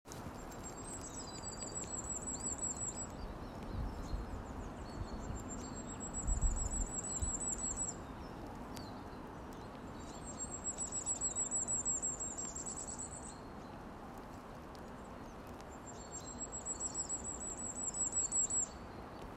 Sārtgalvītis, Regulus ignicapilla
StatussUzturas ligzdošanai piemērotā biotopā (B)